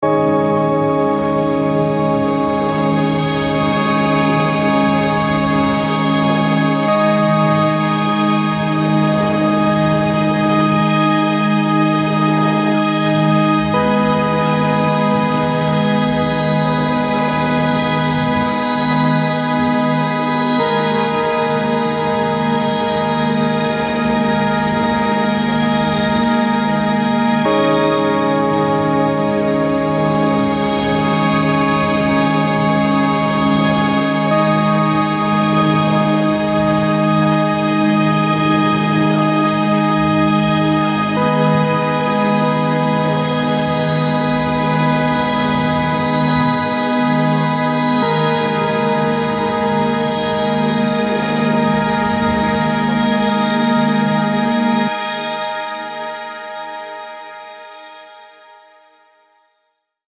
An evolving alchemical synth engine
• An atmospheric synth engine that’s made for motion
Audio demos